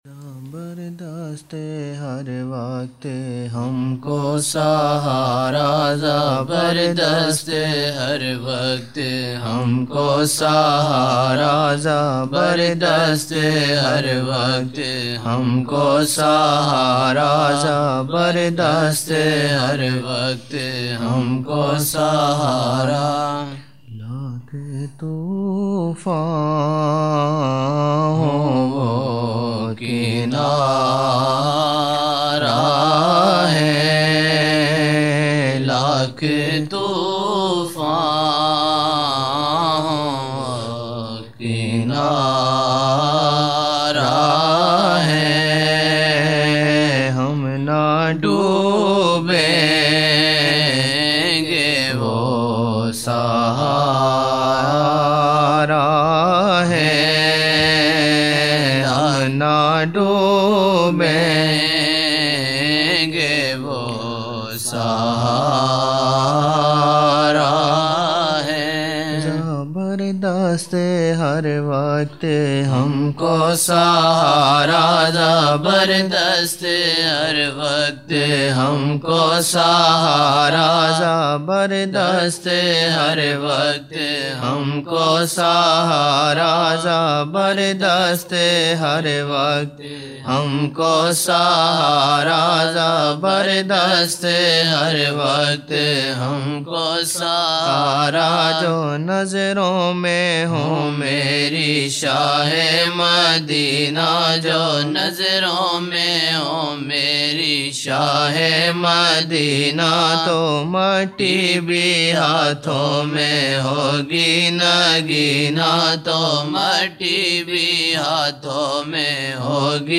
22 November 1999 - Zohar mehfil (14 Shaban 1420)
Aaqa jee saw ka waseela sab say mazboot, waseelay ki ehmiyat, Aaqa jee saw say jurnay say sab kaam banein, shirk ki wazahat, Aapas mein ikhtikaf say Aaqa jee saw ko takleef hoti hai, firqoon kay ikhtilaf say dur rahein, aapas ki mohabbat Naat shareef